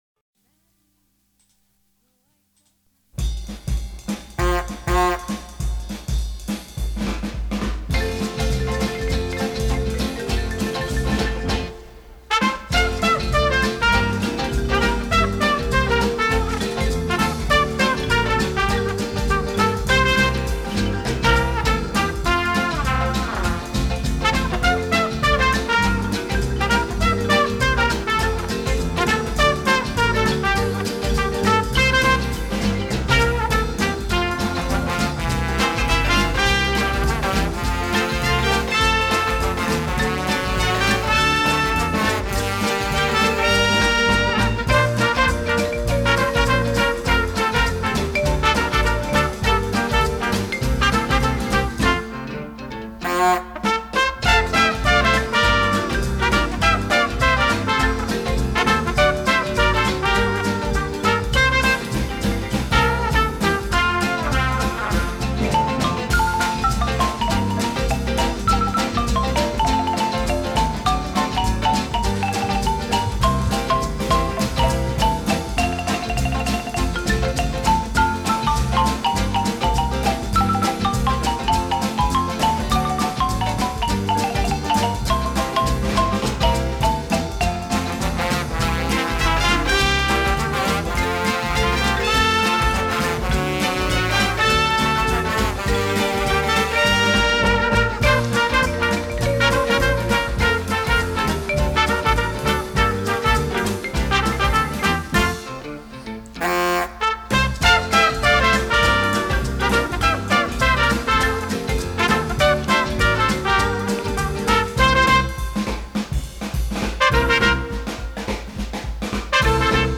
Eguerdiko Ahotsa magazine, informatibo, giza-kultural bat da, eta bertan prentsa errepasoa, elkarrizketak, kaleko iritziak, kolaboratzaileen kontakizunak, musika, agenda eta abar topatu ditzazkezu.